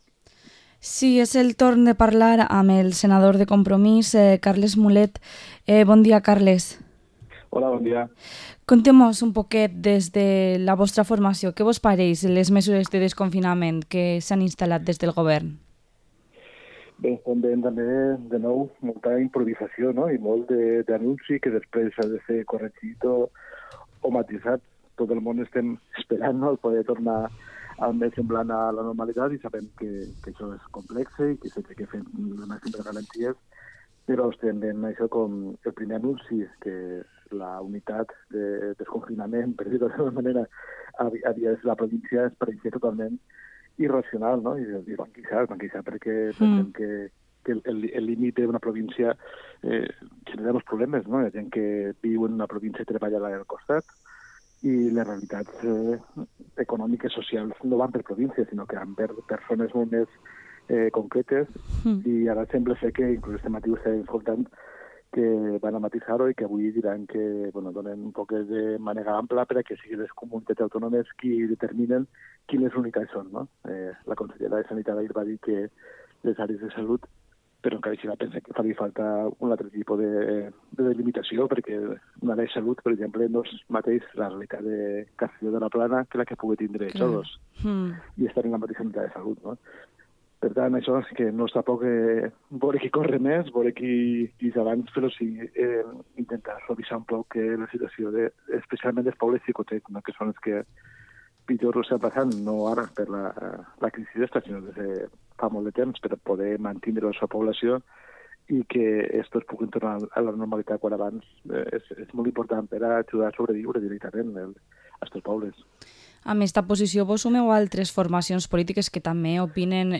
Entrevista al Senador de Compromis, Carles Mulet